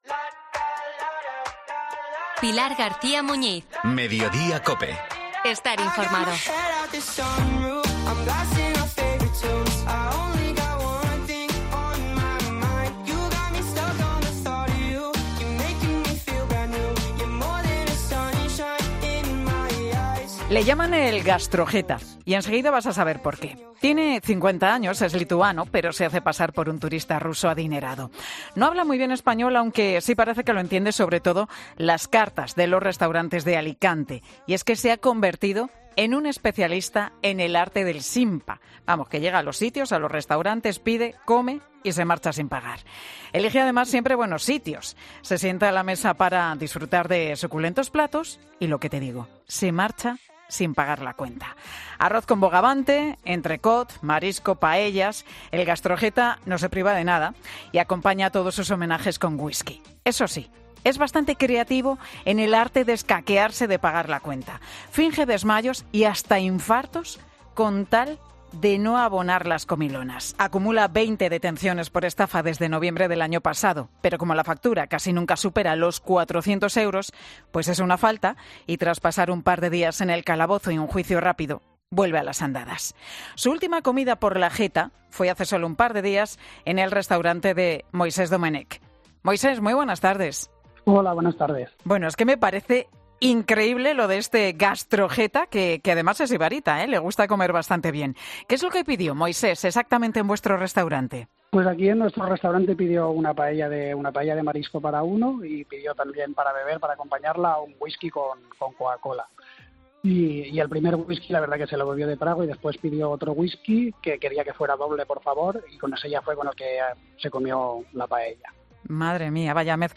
El instructor del caso, en Mediodía COPE